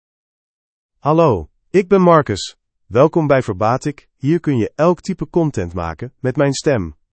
MaleDutch (Netherlands)
Marcus — Male Dutch AI voice
Voice sample
Male
Marcus delivers clear pronunciation with authentic Netherlands Dutch intonation, making your content sound professionally produced.